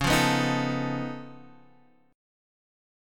Db+9 chord